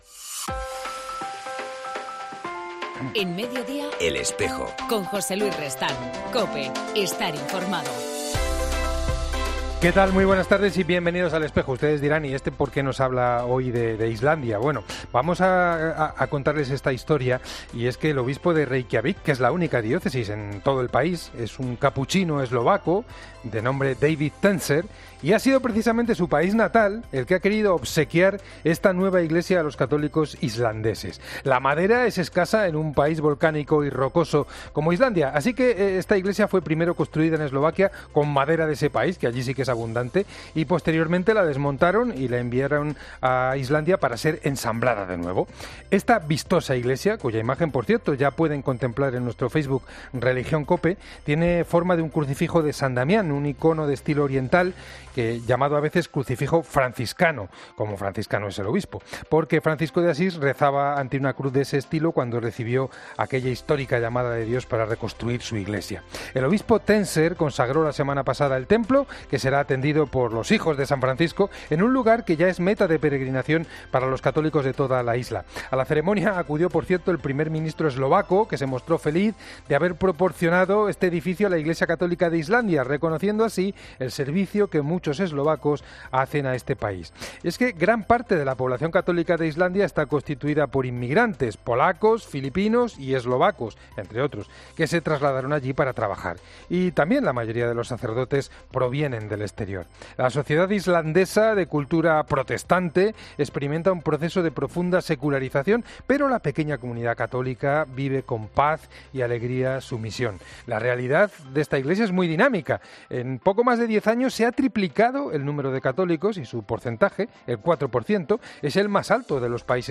entrevista al misionero comboniano